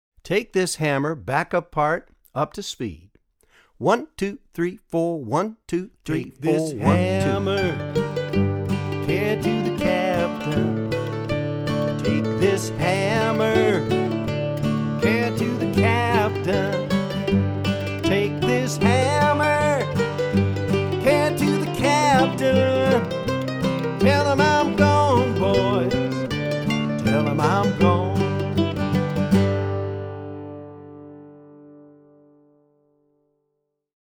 DIGITAL SHEET MUSIC - MANDOLIN SOLO
Traditional Mandolin Solo
both slow and regular speed